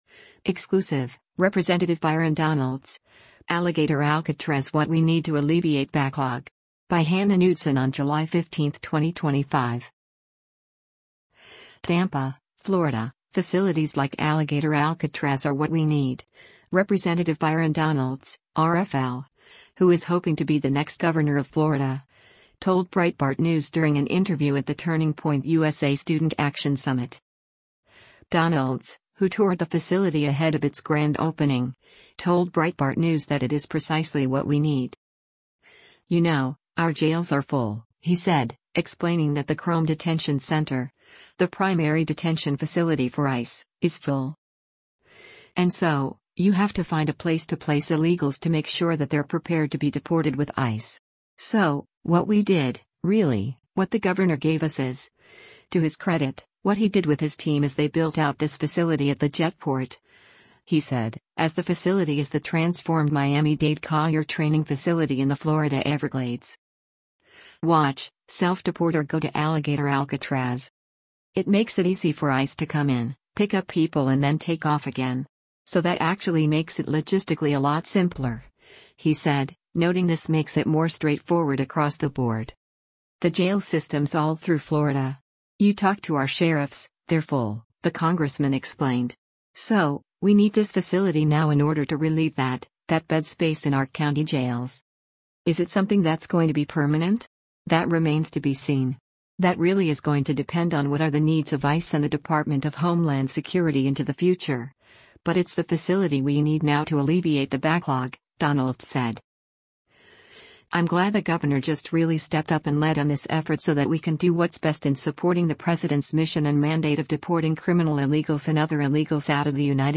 TAMPA, Florida — Facilities like Alligator Alcatraz are “what we need,” Rep. Byron Donalds (R-FL) — who is hoping to be the next governor of Florida — told Breitbart News during an interview at the Turning Point USA Student Action Summit.